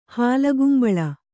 ♪ hālagumbaḷa